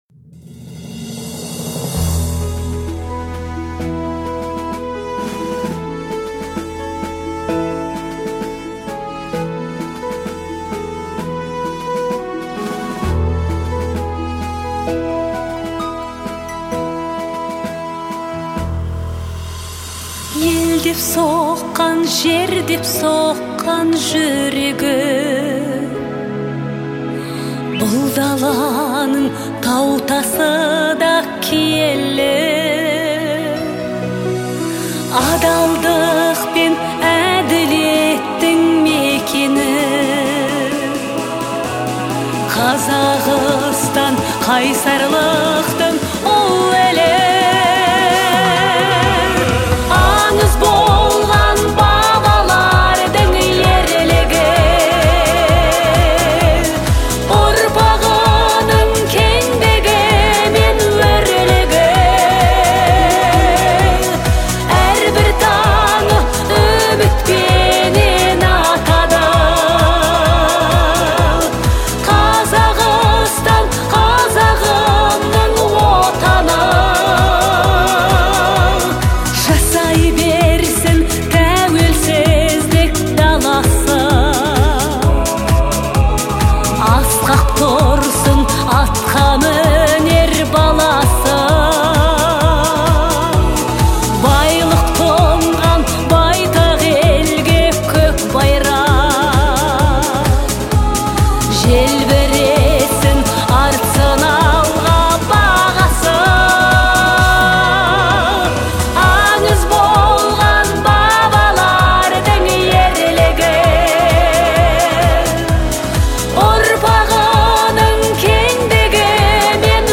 трогательная и патриотичная песня
которая относится к жанру народной и эстрадной музыки.